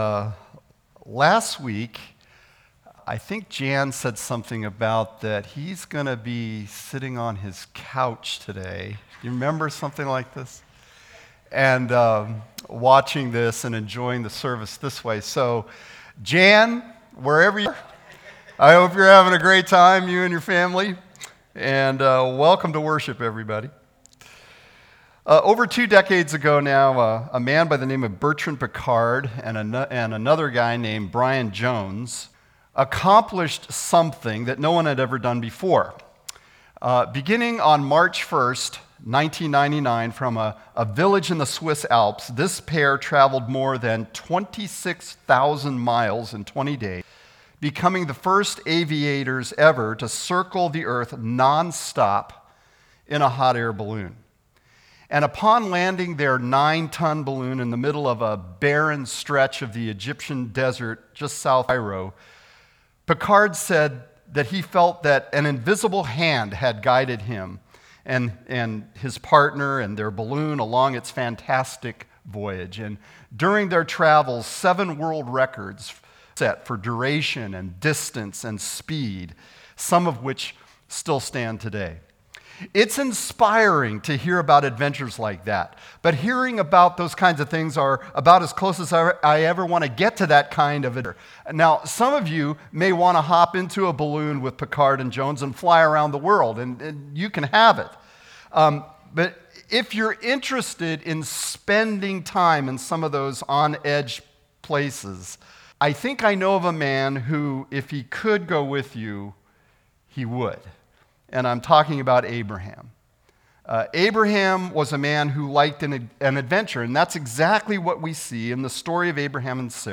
Sermon-8.30.20.mp3